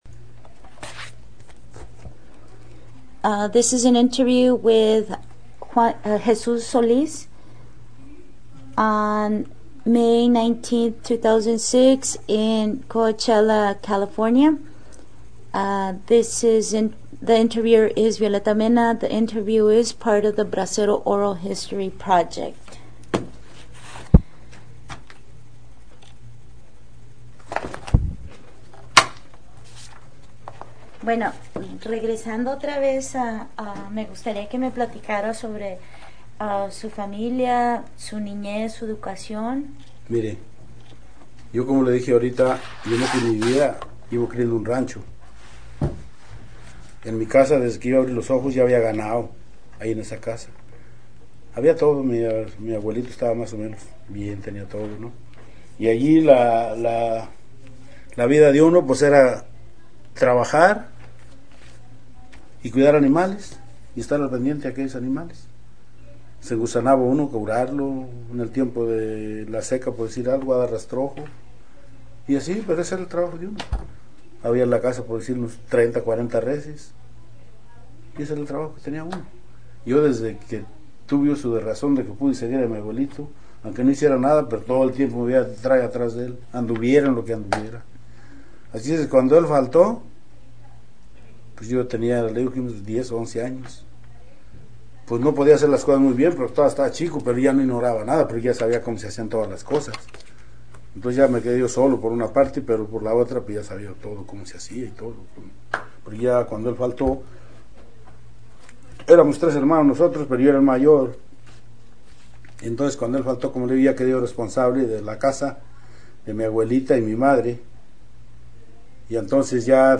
Location Coachella, CA Original Format Mini DIsc